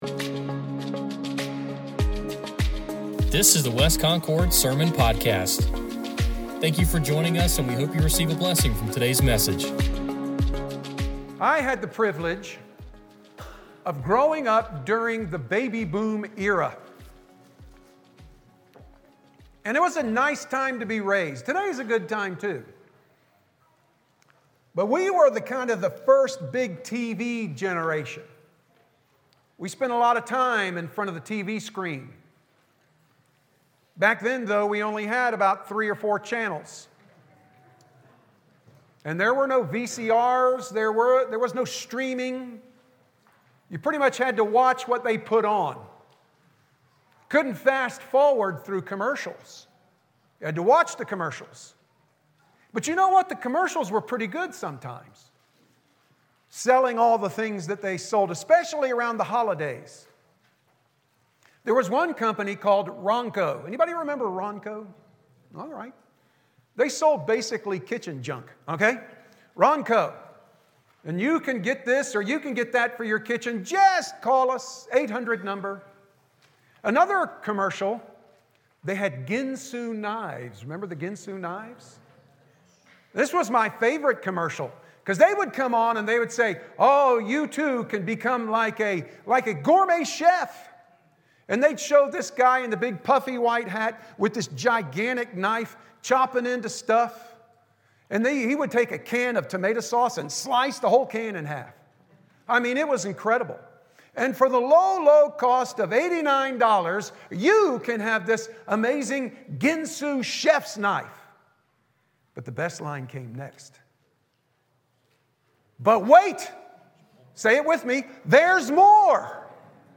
Sermon Podcast | West Concord Baptist Church